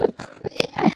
mob / endermen / idle4.ogg